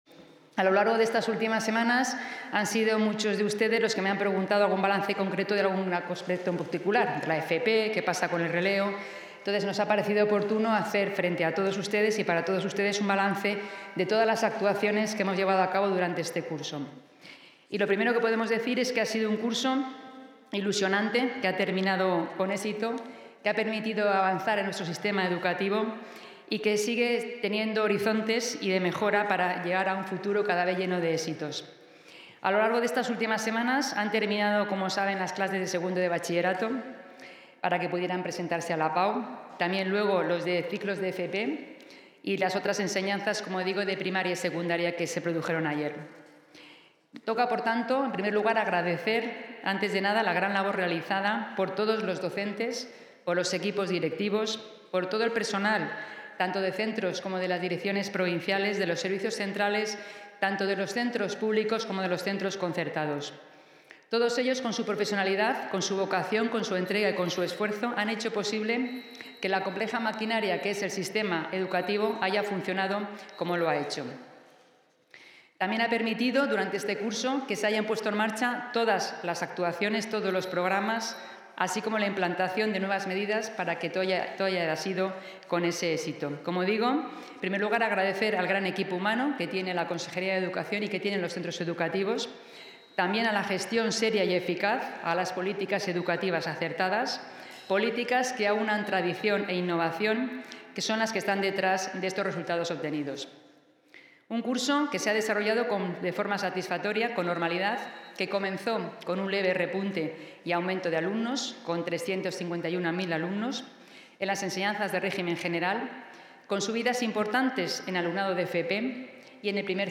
Intervención de la consejera.
La Junta de Castilla y León reafirma su compromiso con unas políticas educativas orientadas a seguir elevando el nivel formativo de la Comunidad. Así lo ha manifestado la consejera de Educación, Rocío Lucas, durante el balance de fin de curso 2024-2025, que ha tenido lugar esta mañana y en el que ha hecho una valoración muy positiva.